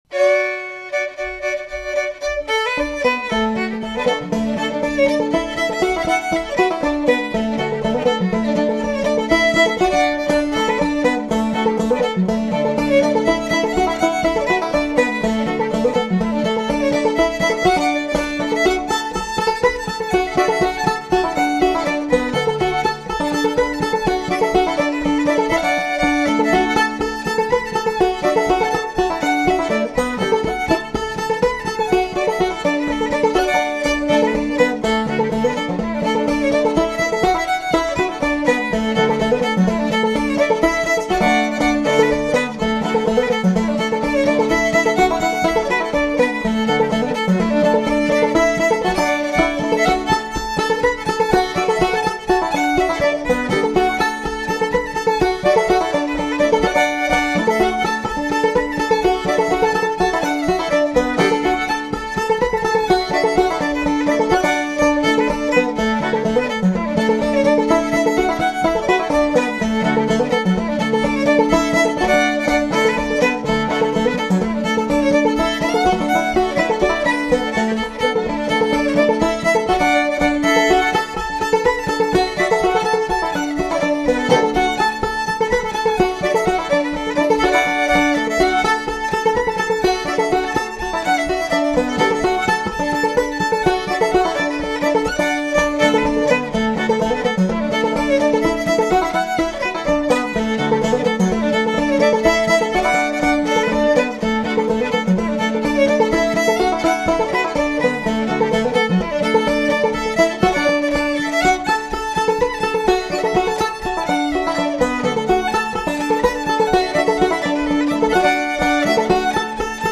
Their fiddle and banjo playing has a lovely, intertwined sound where the "accents accent each other....bouncy, rhythmic, lyrical and expressive..."
fiddle
banjo